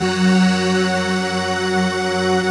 Index of /90_sSampleCDs/Optical Media International - Sonic Images Library/SI1_Breath Choir/SI1_Breathoctave